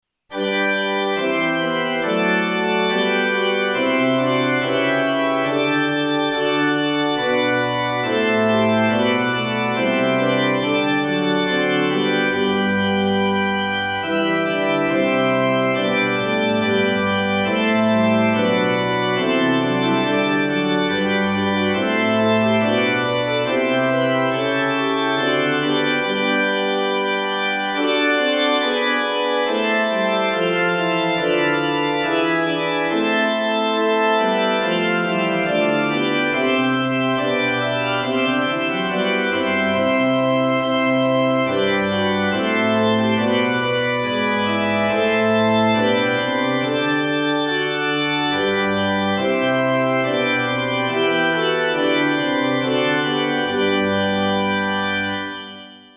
1　 Organ